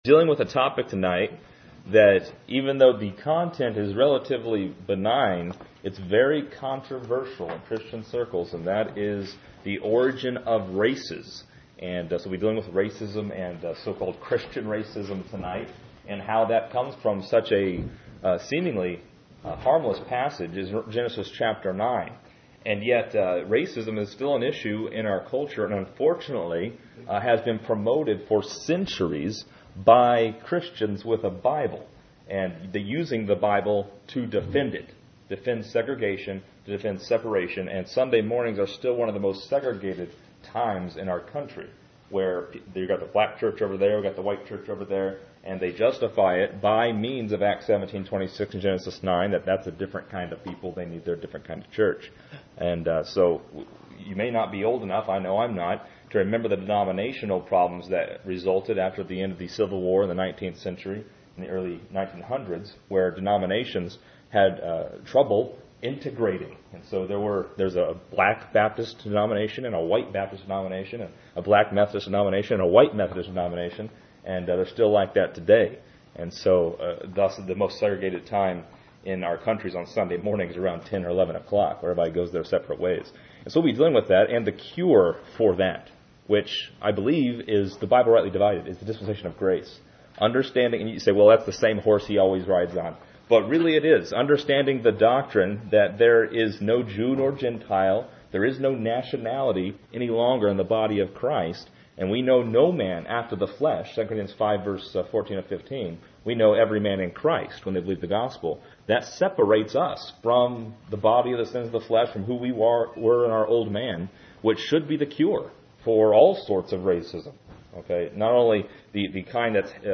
This lesson is part 19 in a verse by verse study through Genesis titled: Noah’s Sons and Racism.